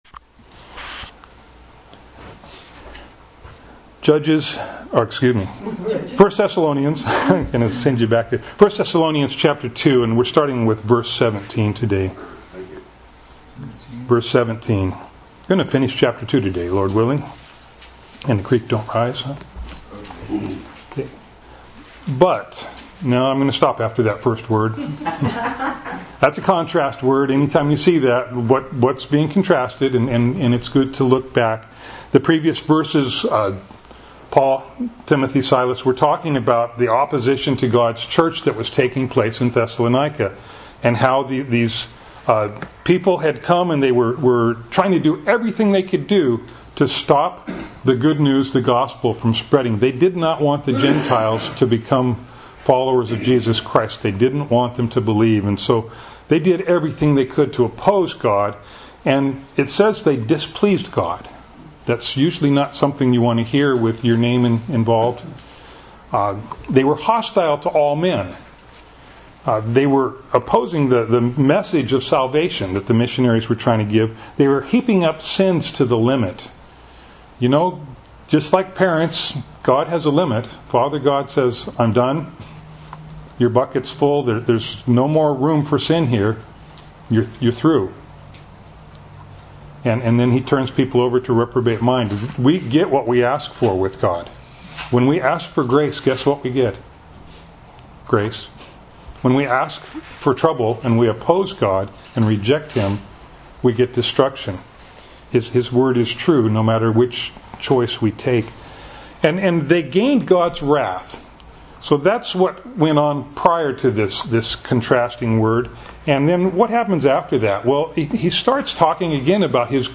There are two audio files that go with this sermon day.
Service Type: Sunday Morning